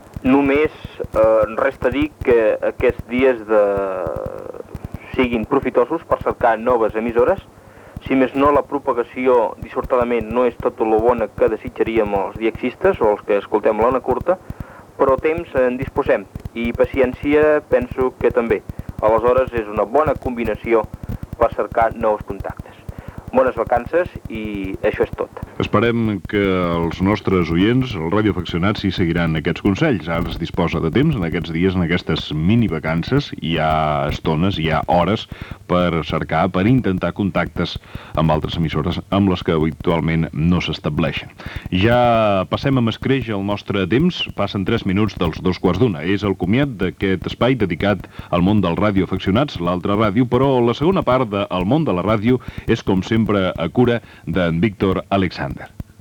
Comiat del programa